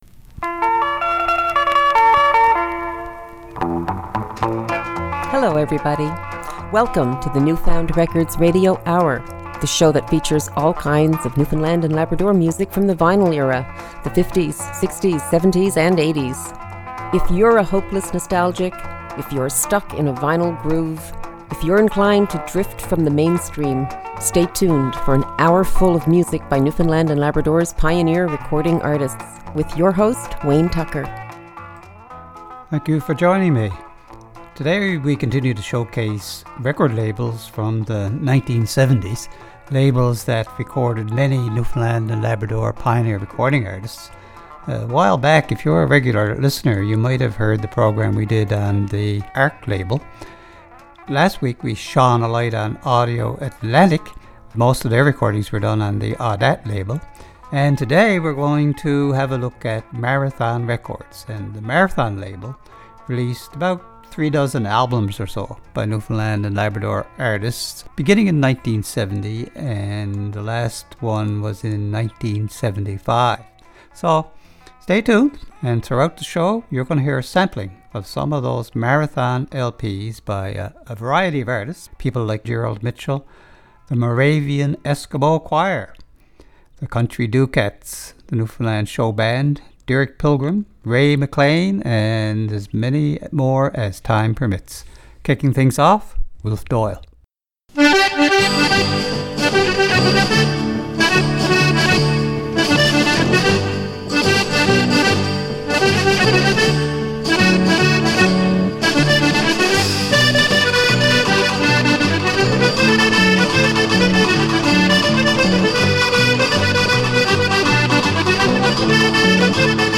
Recorded at CHMR studios, MUN, St. John's, NL.